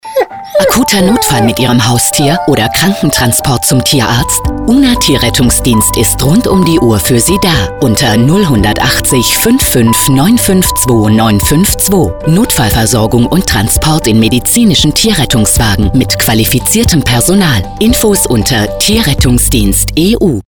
radiospot.mp3